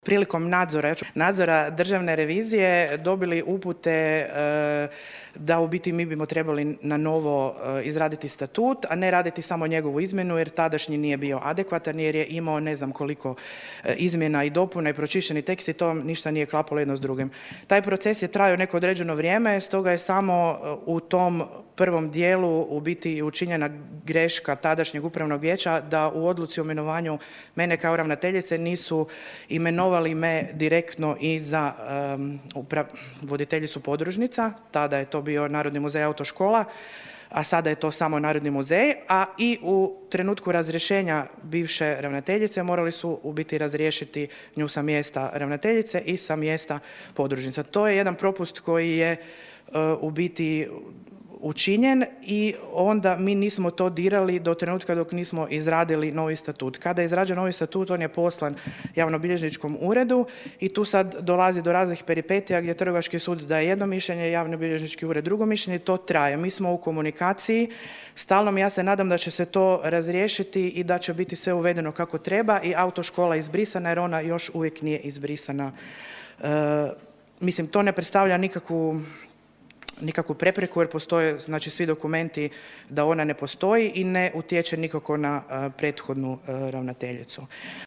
Gradsko vijeće Labina nije na današnjoj sjednici prihvatilo Izvještaj o radu Pučkog otvorenog učilišta za 2024. godinu.